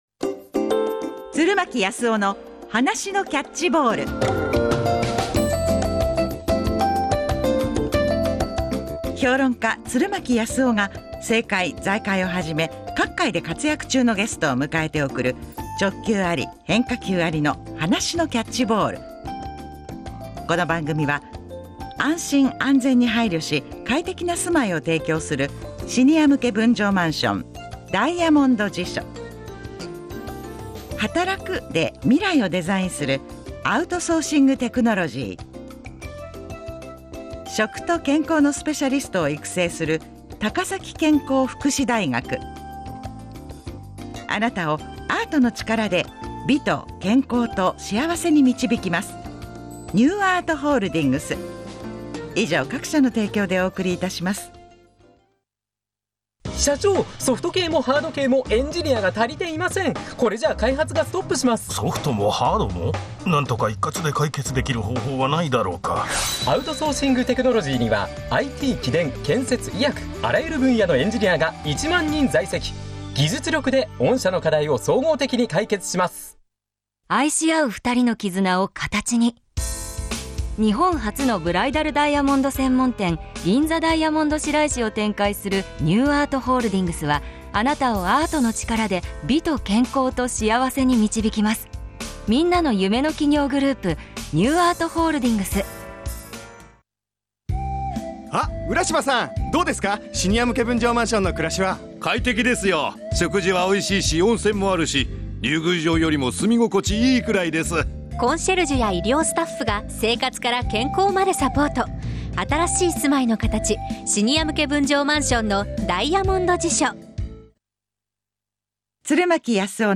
ラジオ出演（放送音声あり）